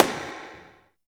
90 SNARE 2-L.wav